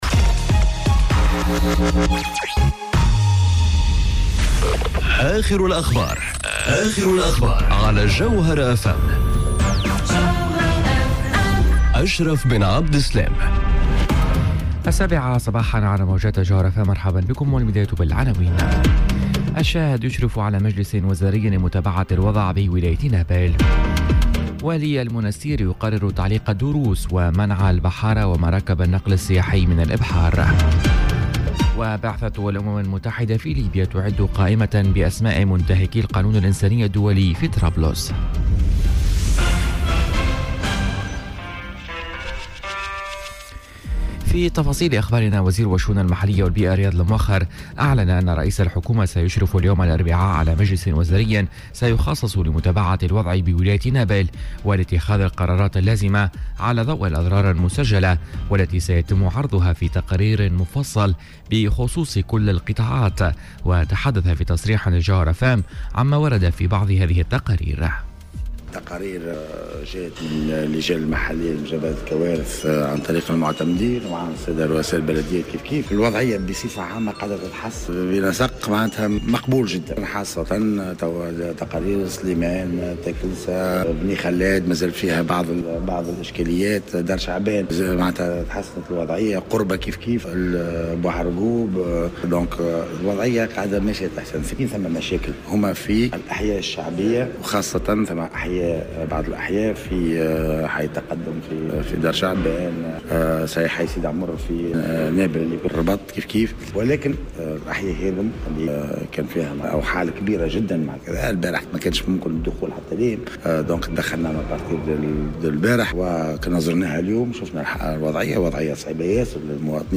نشرة أخبار السابعة صباحا ليوم الإربعاء 26 سبتمبر 2018